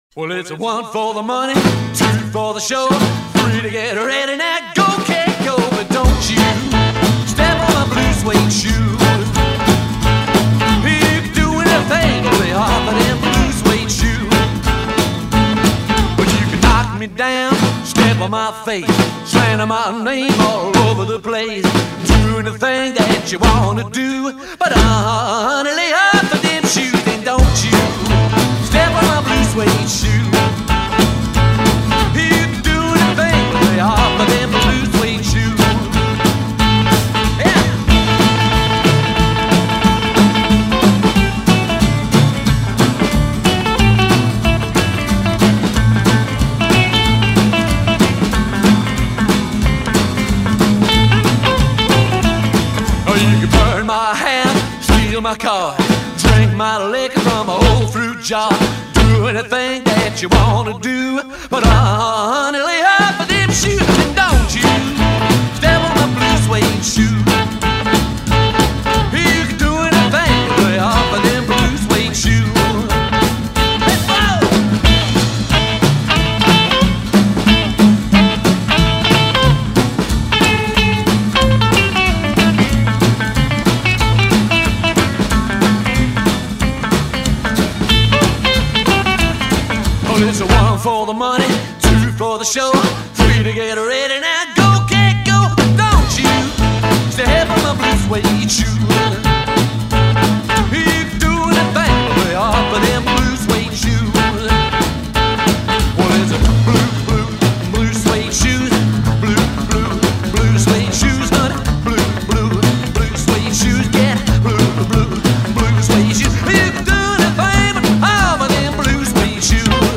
Rock ‘n’ Roll classics